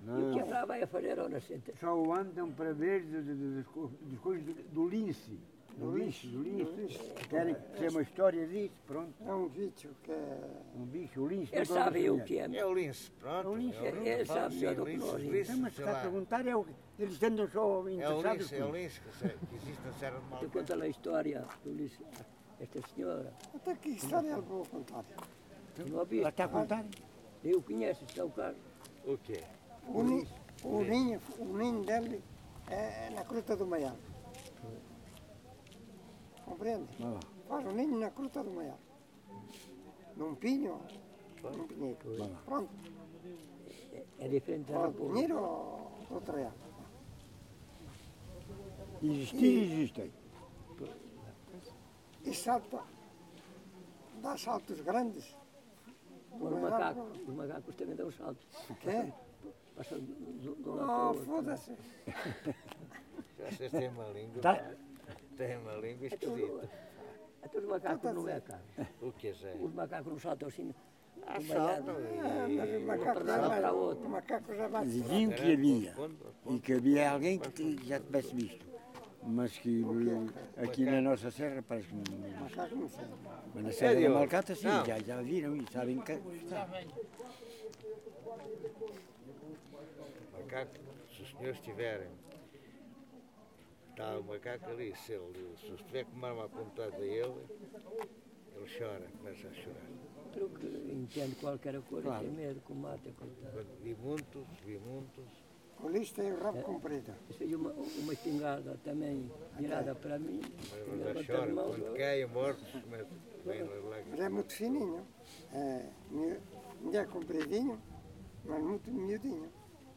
Conversation with local people regarding the Iberian Lynx, recorded in Vale do Espinho (Sabugal, Portugal) in 2012 – Original version, not included in the installation
Conversa com habitantes de Vale do Espinho (Sabugal) a propósito do Lince Ibérico, registo de 2012 – Versão original do audio, não incluída na instalação